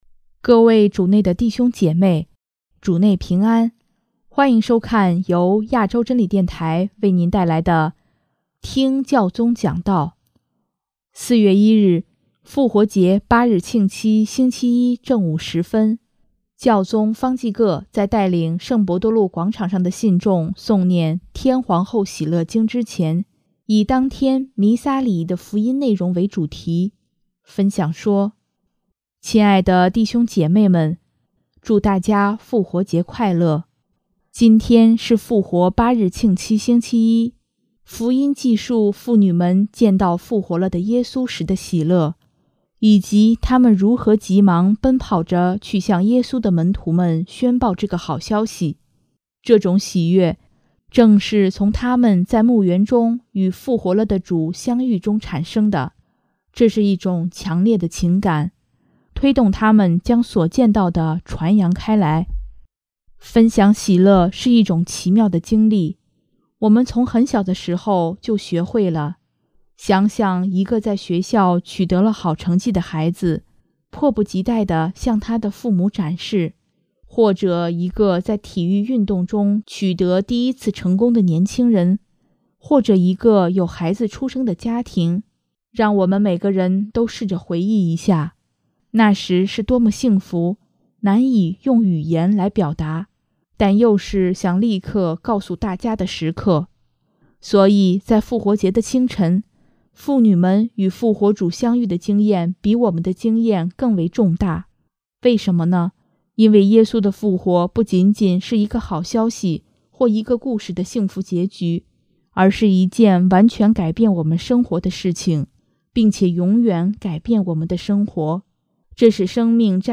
【听教宗讲道】|和耶稣一起，每个“今天”都能期望“明天”
4月1日，复活节八日庆期星期一正午时分，教宗方济各在带领圣伯多禄广场上的信众诵念《天皇后喜乐经》之前，以当天弥撒礼仪的福音内容为主题，分享说：